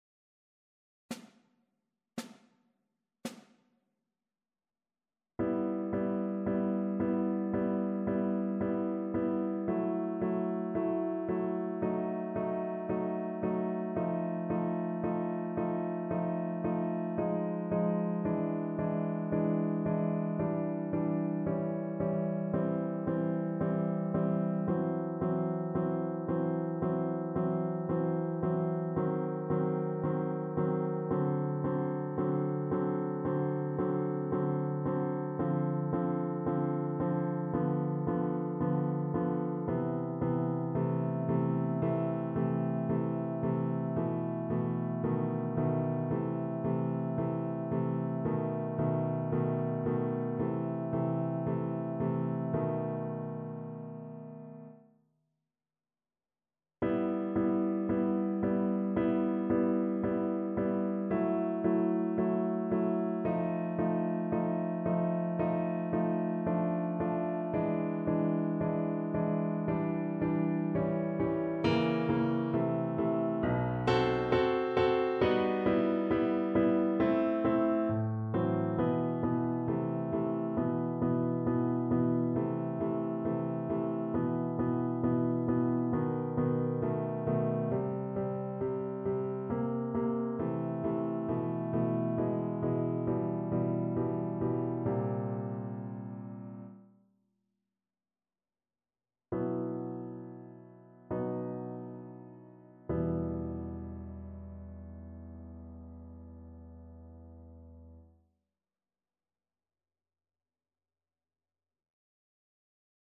Chopin: Preludium e-moll (na wiolonczelę i fortepian)
Symulacja akompaniamentu